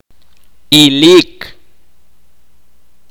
[ʔa.t͡ʃi.’χil] sustantivo inalienable husband